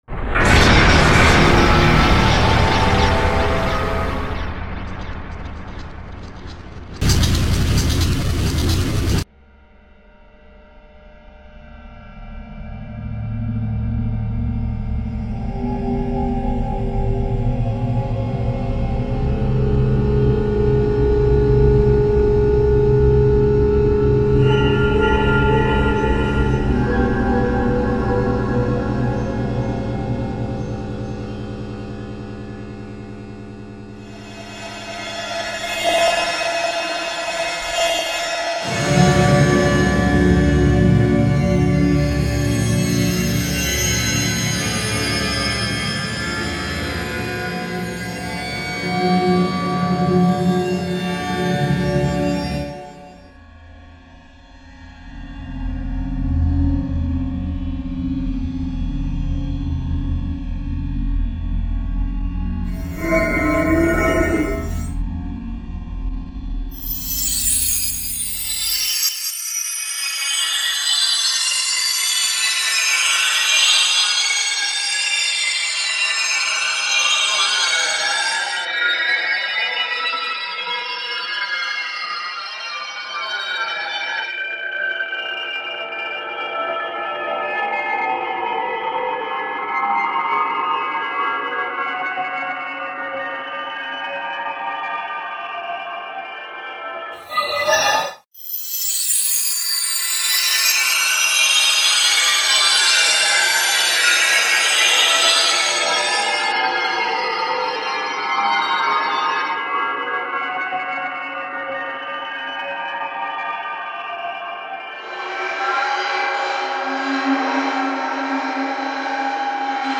ELECTRONIC COMPUTER MUSIC
Nell'anno della celebre Odissea nello spazio, mi è così venuta l'associazione tra l'utilizzo di questi samples e l'uso che negli anni '50 si faceva della sperimentazione elettronica, un uso di estraniazione futurista,  angosciante e inquietante.
La prima metà del brano utilizza esclusivamente questi campionamenti commerciali, appena trattati con ritardi e filtri, quasi a disegnare un mondo sognato da cartolina o di tipo televisivo.
La seconda metà oltre ad utilizzare altri campioni, prevede suoni creati con C-Sound e vorrebbe suggerire tutt'altra ...sgradevole ed inesorabile situazione.